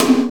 TOM ROKK T0I.wav